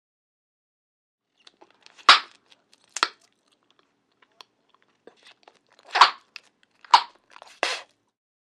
DINING - KITCHENS & EATING BUBBLE GUM: INT: Pops & chewing.